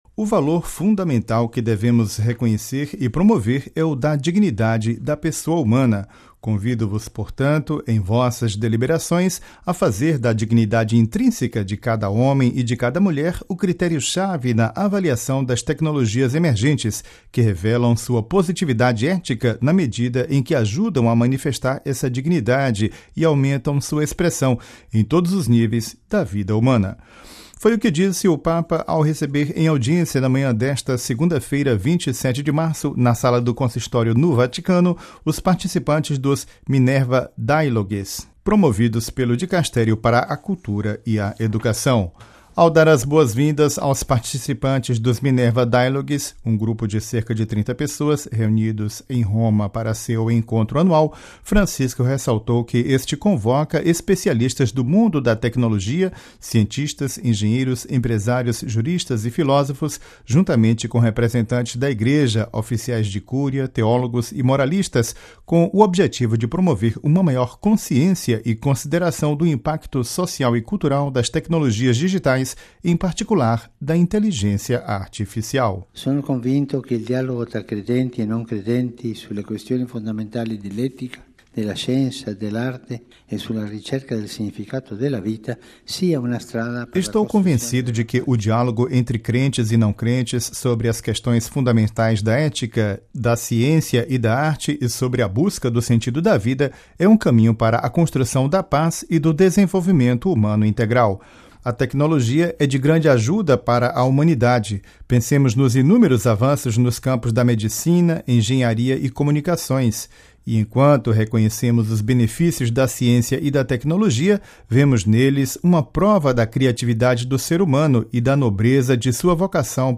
Ouça a reportagem com a voz do Papa e compartilhe